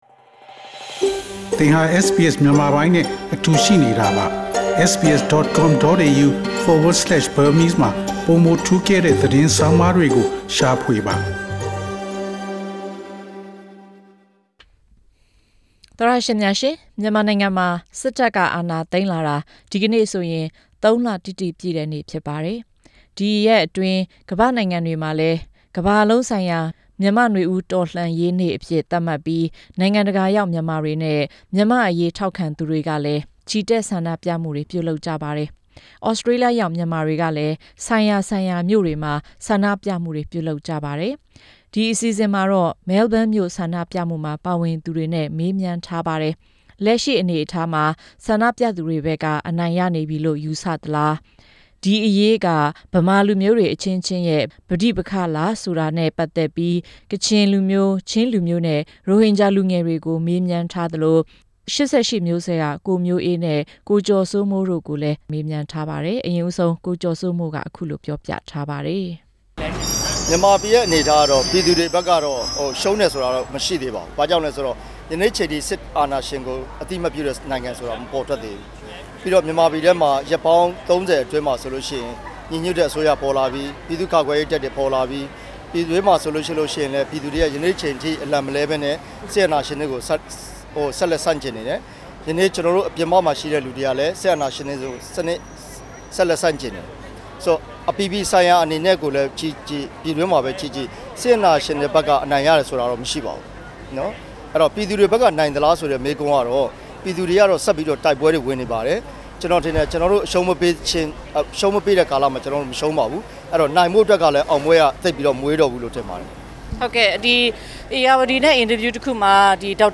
ဒီအစီအစဉ်မှာတော့ မဲလ်ဘုန်းမြို့ ချီတက်မှုမှာ ပါဝင်သူတွေနဲ့ တွေ့ဆုံမေးမြန်းထားပြီး လက်ရှိ အနေအထားအပေါ် ခံစားချက်တွေနဲ့ ဒီပဋ္ဋိပက္ခက ဗမာလူမျိုးတွေ အချင်းချင်းရဲ့ ကိစ္စဟုတ်မဟုတ် ဆိုတာနဲ့ ပတ်သက်ပြီး ကချင်လူငယ်၊ ချင်းလူငယ်၊ ရိုဟင်ဂျာ လူငယ်တွေရဲ့ အမြင်ကို မေးမြန်းထားတဲ့အပြင် ၈၈ မျိုးဆက် တို့ကိုလည်း မေးမြန်းထားပါတယ်။